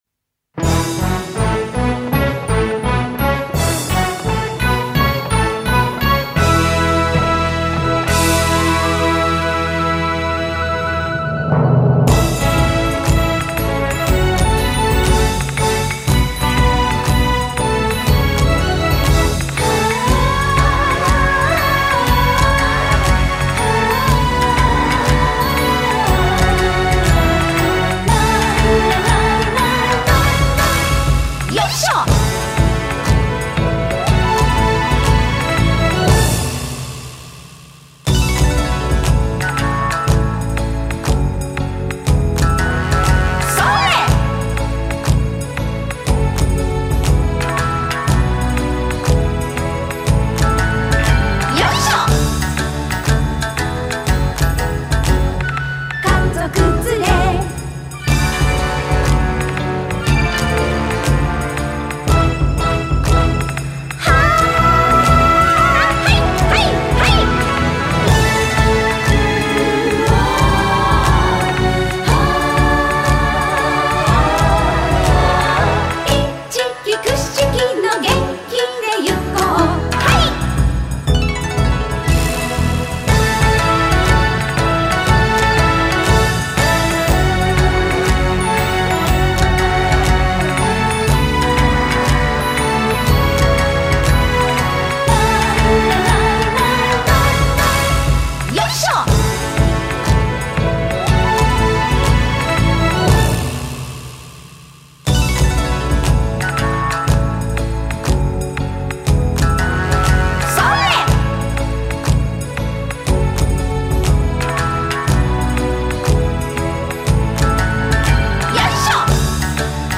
伴奏のみ